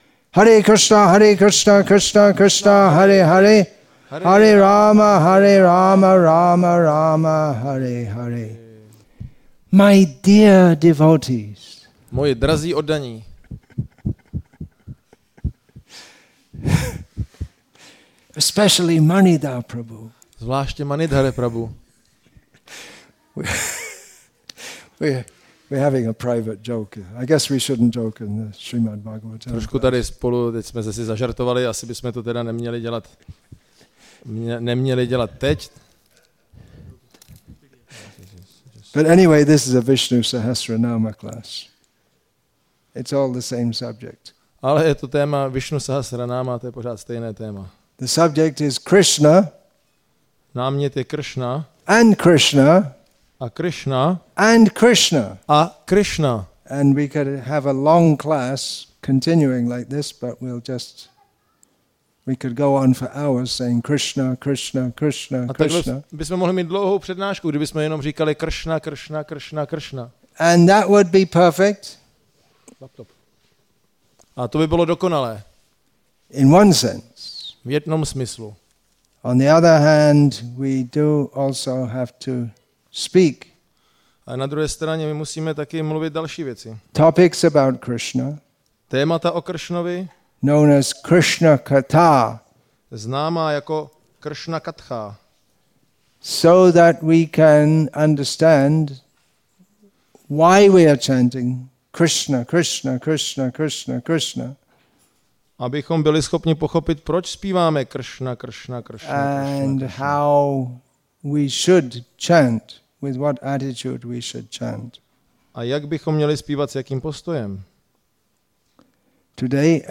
Nava Gokula Farm, Czech Republic